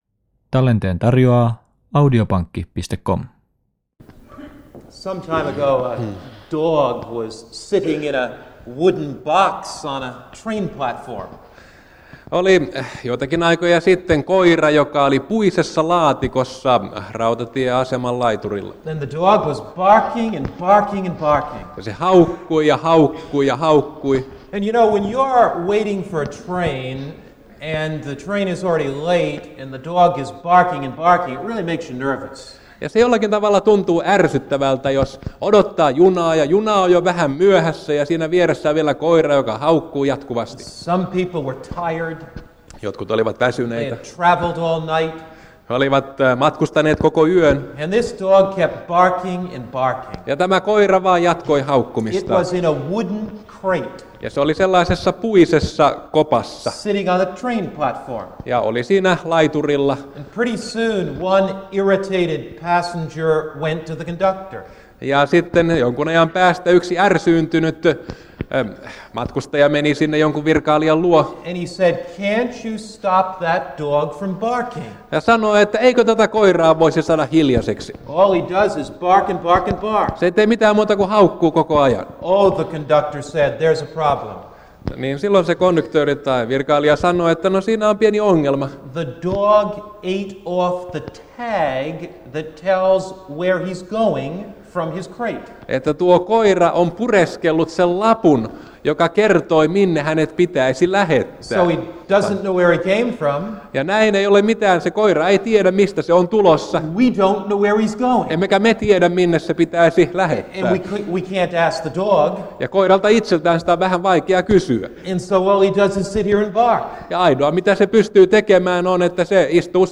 Helsingissä 1980-luvulla